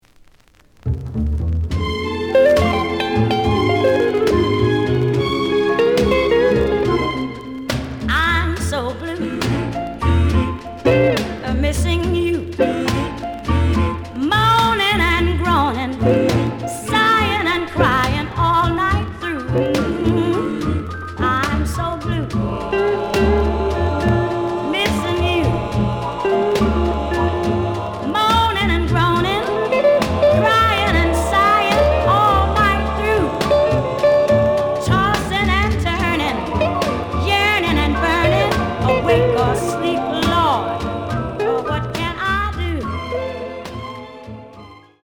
The audio sample is recorded from the actual item.
●Genre: Vocal Jazz